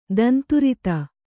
♪ danturita